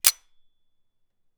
jamming_pistol.wav